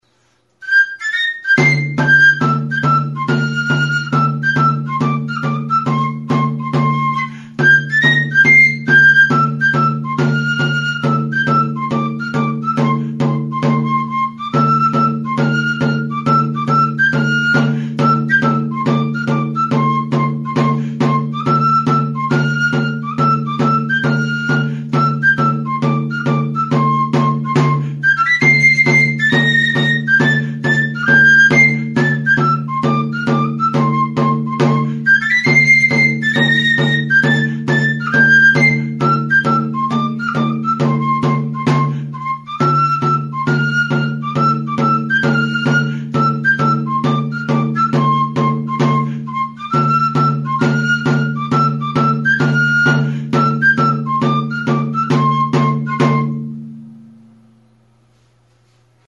Aerófonos -> Flautas -> Recta (de una mano) + flautillas
Grabado con este instrumento.
Hiru zuloko flauta zuzena da.